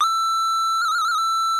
In practice, you want to add some vibrato in the middle of a long note (do it at your own discretion, depends on each particular track).
Audio: PSG guitar (longer note), with vibrato
psg-guitar-2.mp3